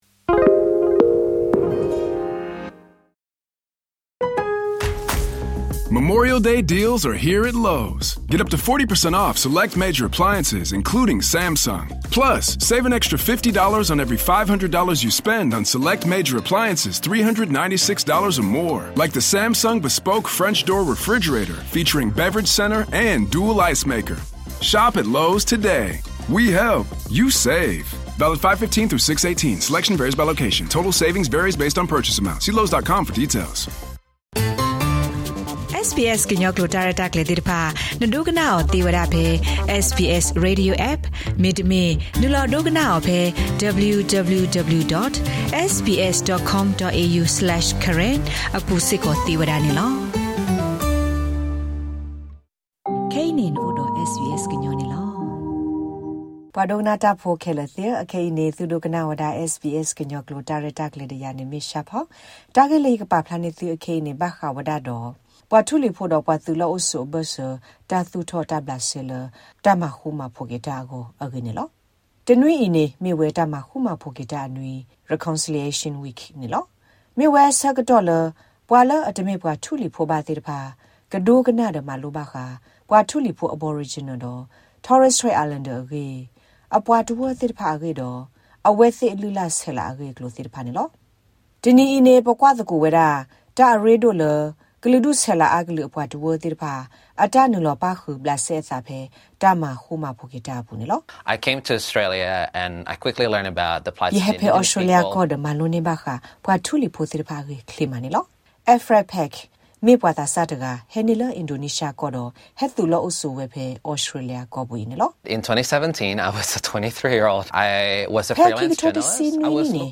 Message preached